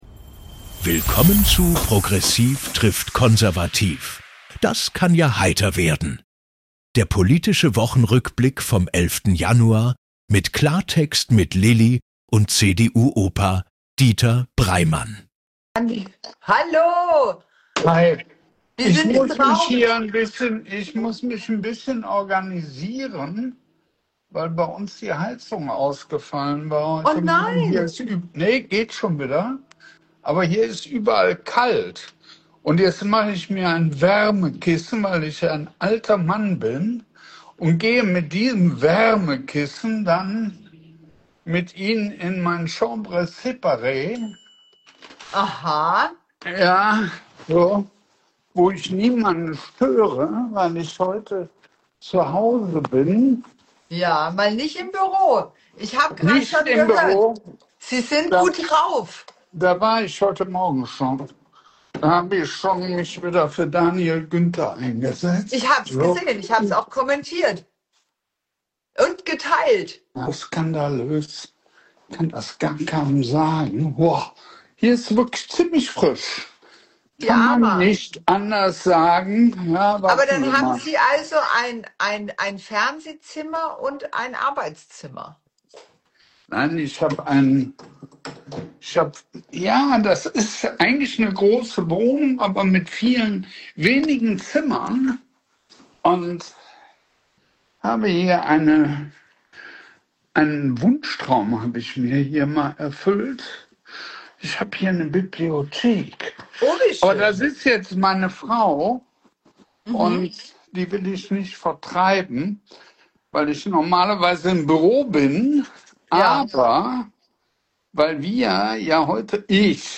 zwei Perspektiven, ein Gespräch